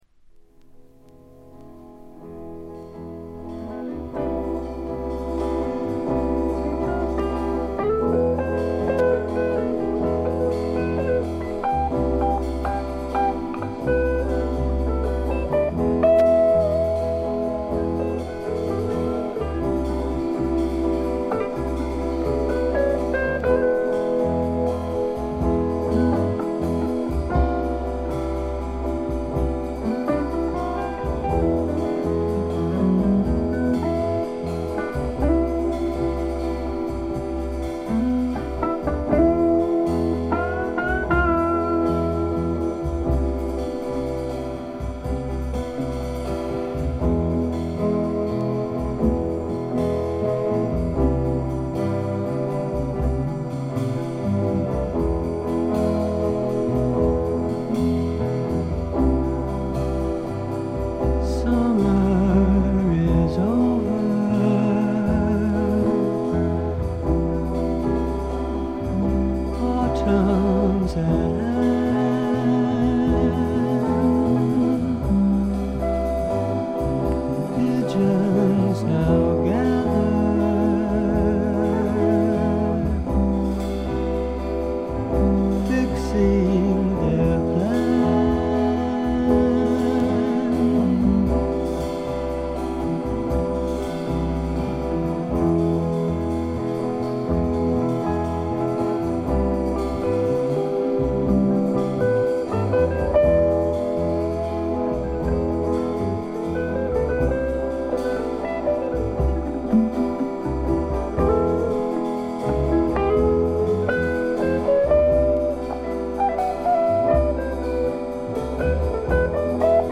というわけで一度聴いたらクリアトーンのギターの音色が頭から離れなくなります。
特異な世界を見せつけるアシッド・フォークの傑作です。
試聴曲は現品からの取り込み音源です。
Guitar, Vocals, Producer, Written-By, Arranged By ?